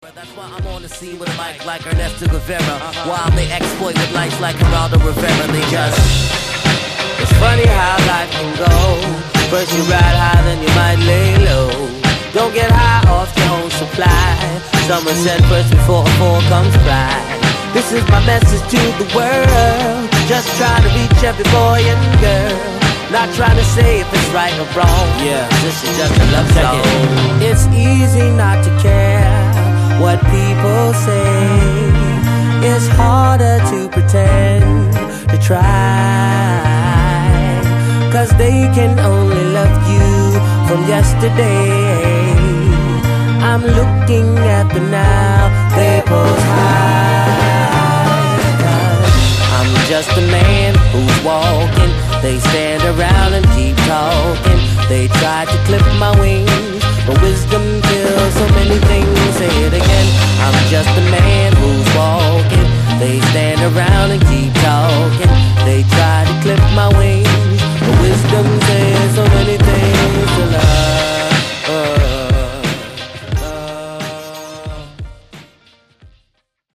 String arrangement